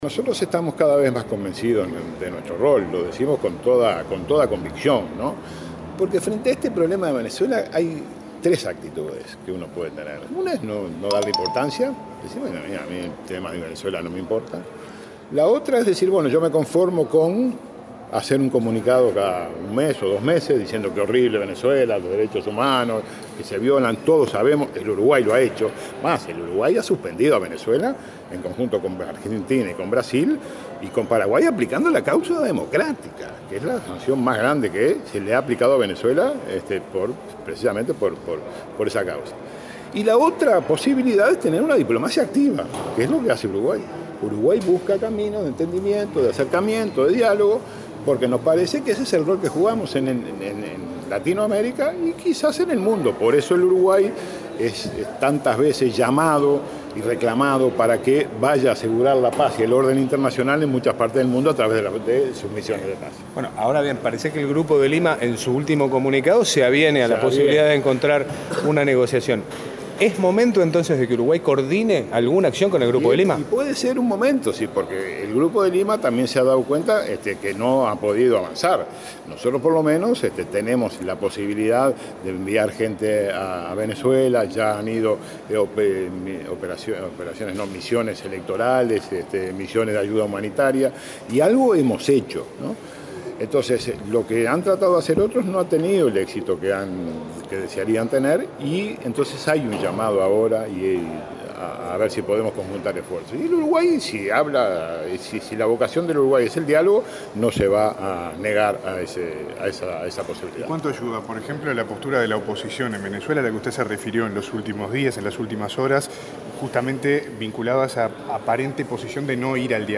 “La única manera de salir de la crisis que vive Venezuela es la negociación, salvo que se busque un golpe de Estado, una guerra civil o la invasión de una potencia extranjera”, advirtió el canciller Nin Novoa a la prensa. Recalcó que una diplomacia activa es la postura de Uruguay en esta situación.